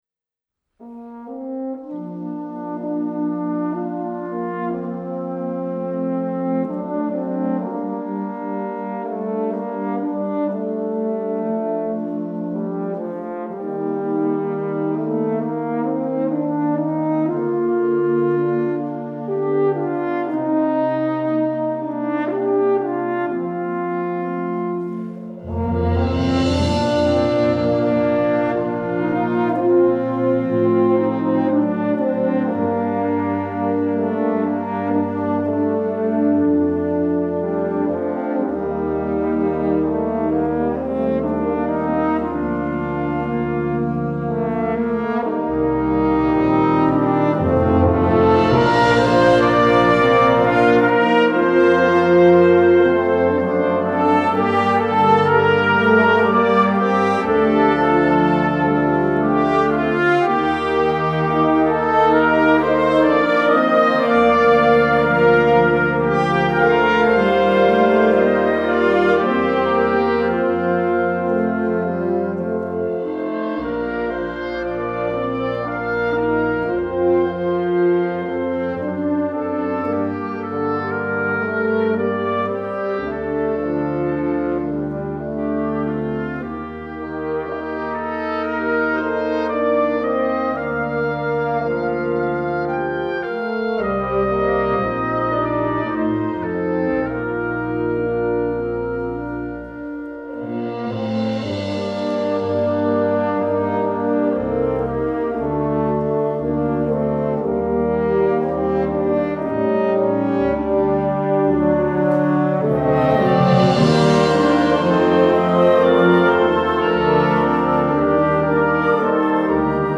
Gattung: Suite für Horn und Blasorchester
Besetzung: Blasorchester